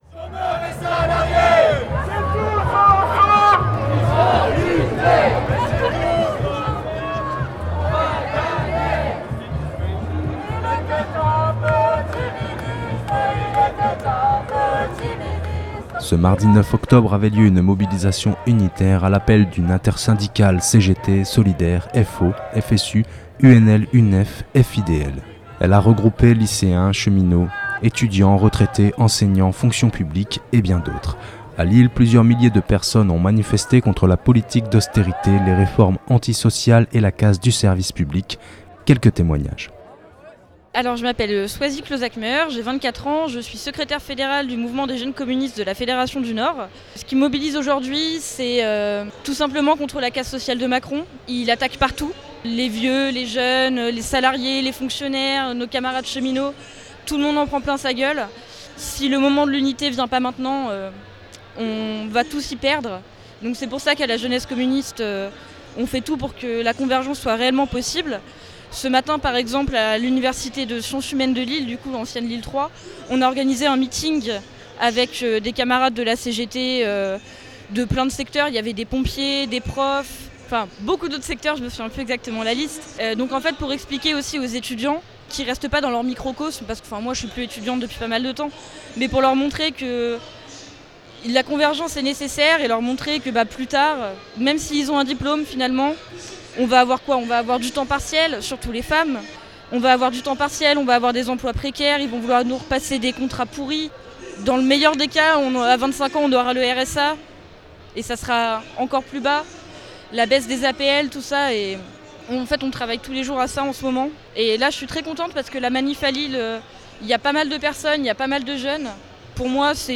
Quelques témoignages recueillis sur place: